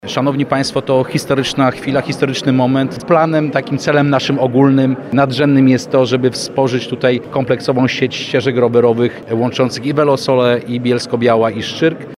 Przemysław Koperski, wiceminister infrastruktury mówił, że przekazanie umowy to historyczna chwila i podkreślał, że nadrzędnym celem jest stworzenie sieci ścieżek, które połączą istniejącą już trasę Velo Soła, Bielsko-Białą i Szczyrk.